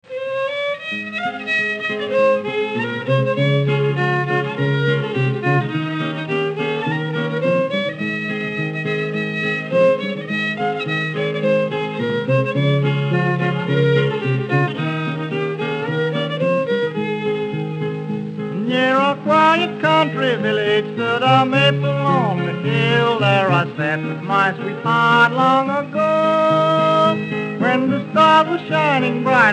fiddle
guitar
vocals